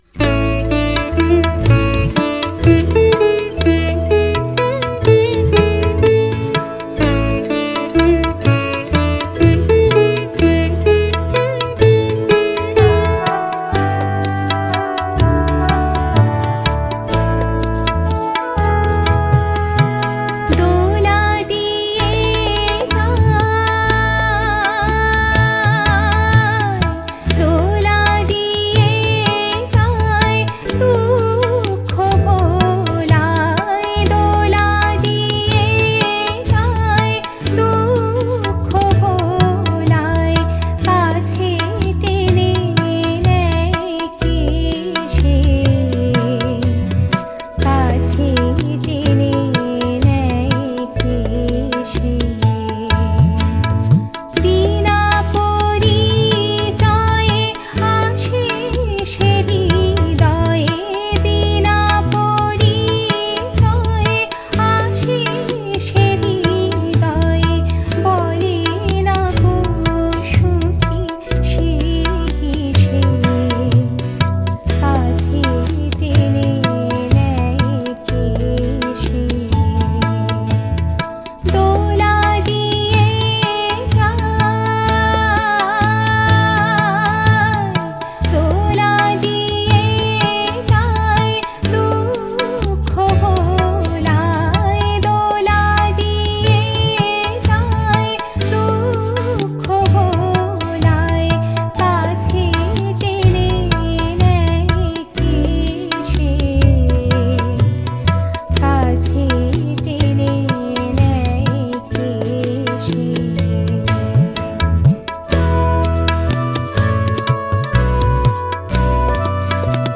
Music Kaharva